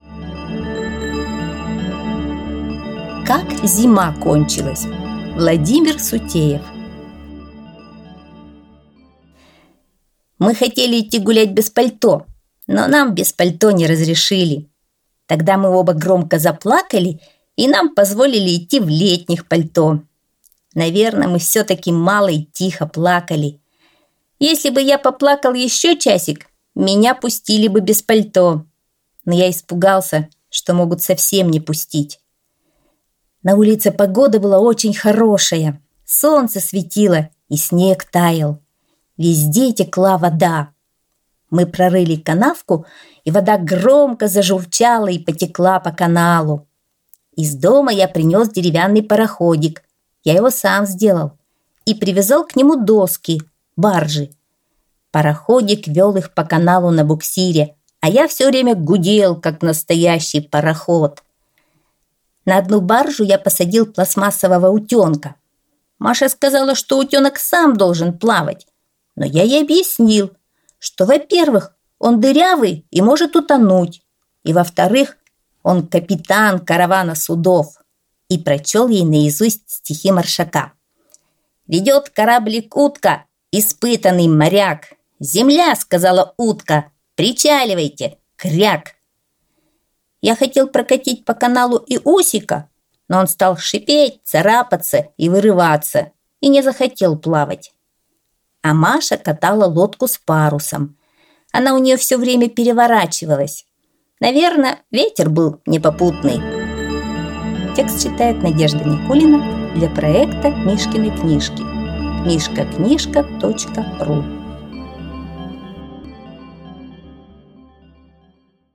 Аудиосказка «Как зима кончилась»